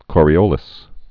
(kôrē-ōlĭs)